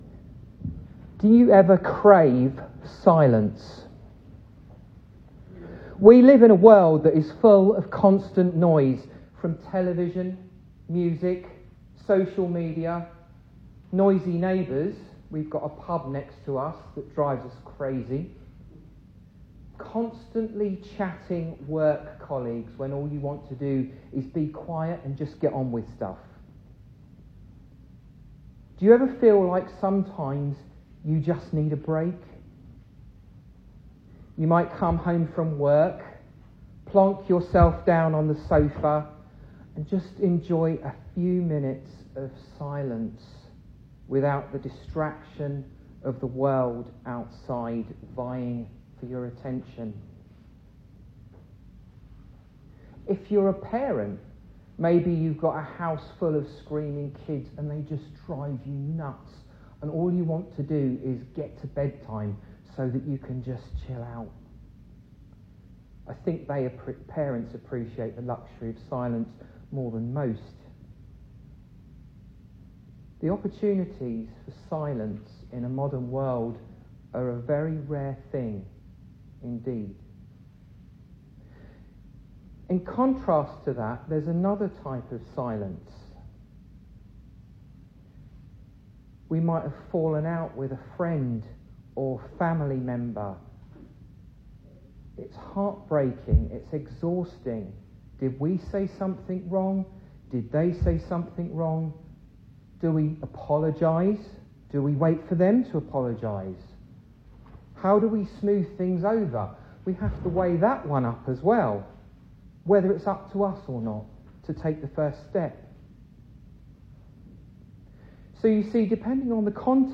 Three Ways To Approach Worship (low quality recording) - Bury St Edmunds Presbyterian Church
2026 Service Type: Sunday Evening Speaker